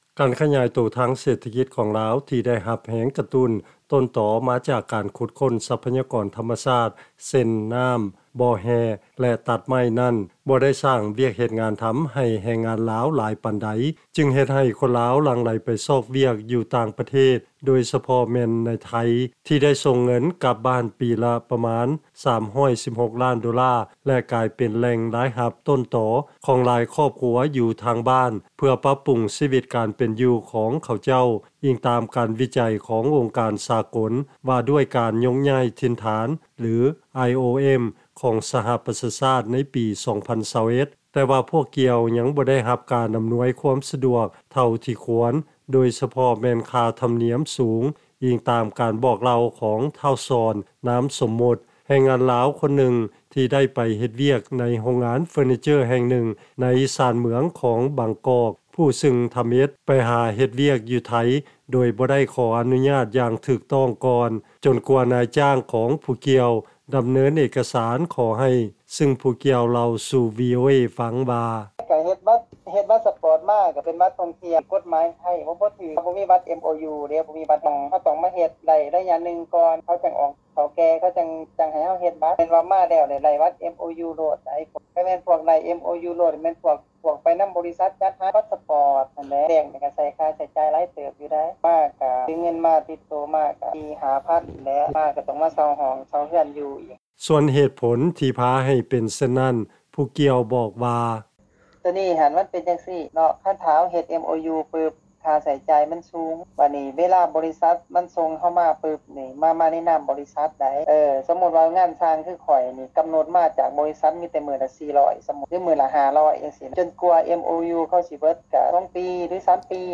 ຟັງລາຍງານ ແຮງງານລາວຢູ່ໄທ ສົ່ງເງິນກັບບ້ານ ປີລະ 3 ລ້ານກວ່າໂດລາ ແຕ່ຍັງບໍ່ໄດ້ຮັບຄວາມສະດວກ ຢ່າງພຽງພໍຈາກພາກສ່ວນຕ່າງໆ